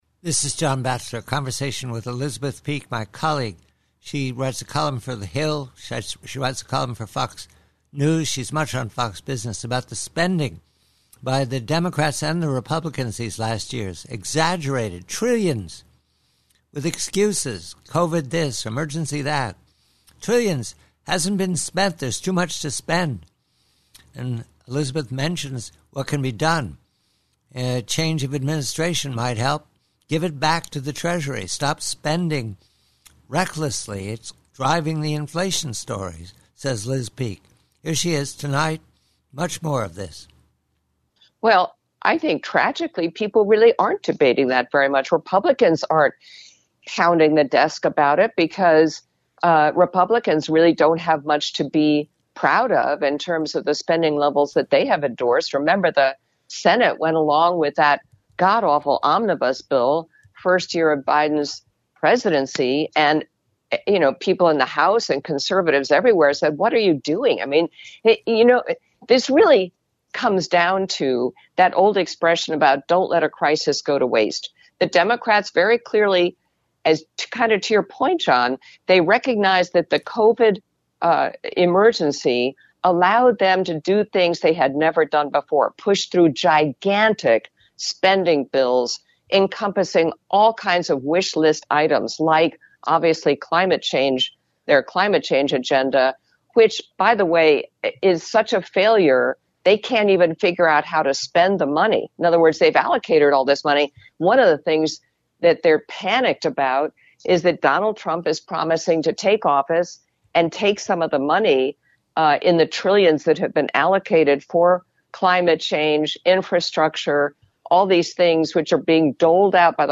PREVIEW: SPENDING: Conversation with colleague Elizabeth Peek of The Hill and Fox News re the gargantuan spending by both parties in Washington, especially by the Biden Administration, that drives inflation and cannot be contained until there is a change